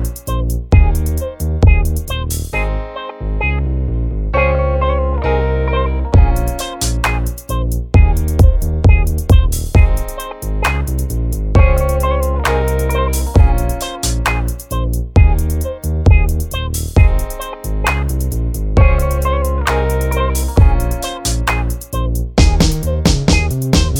Pop (1990s)